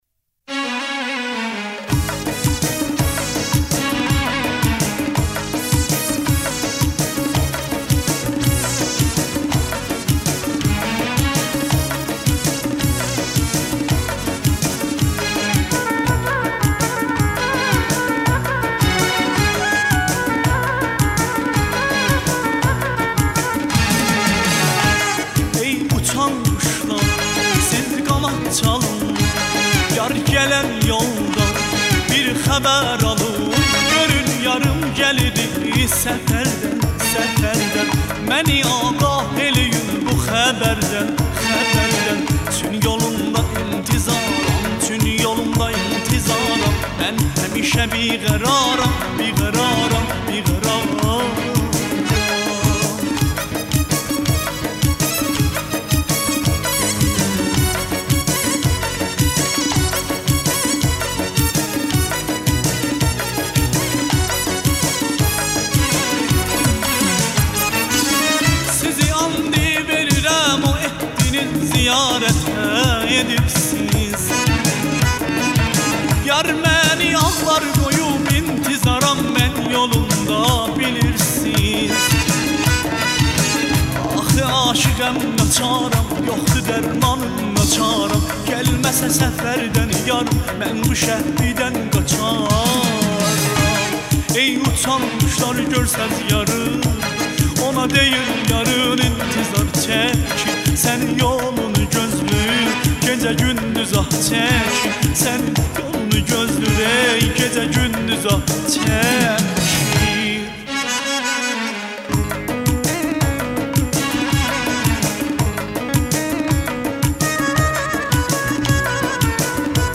موسیقی آذری